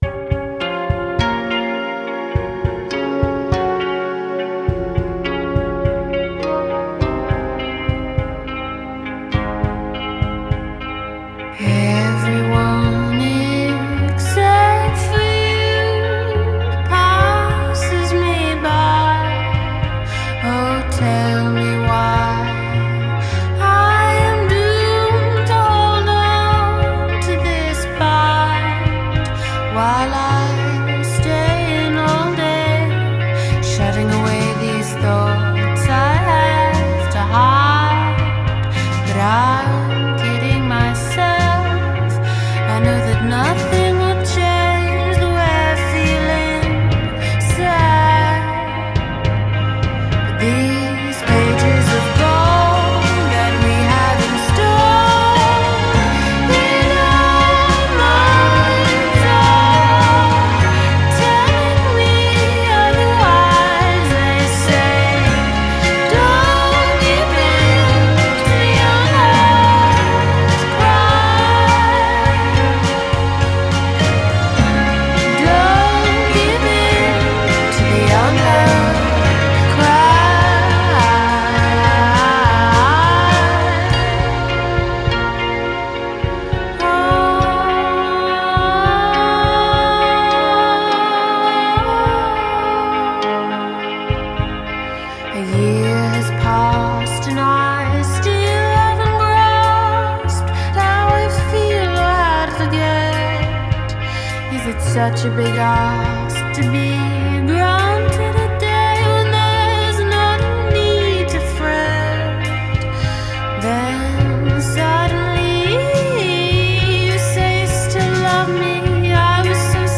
Genre: Indie Folk